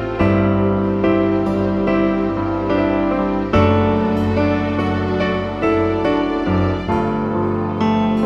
Prelude Section Easy Listening 1:11 Buy £1.50